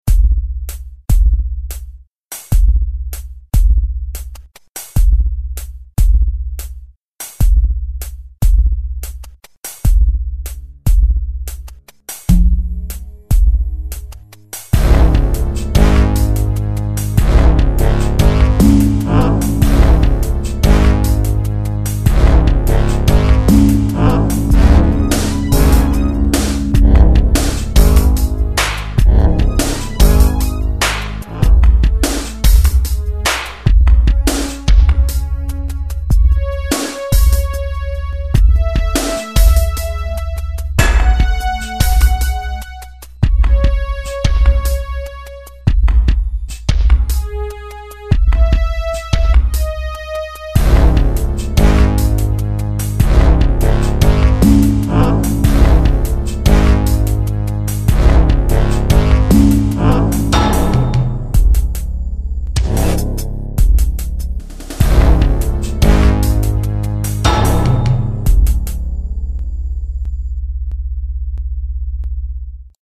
Lyrics [Instrumental]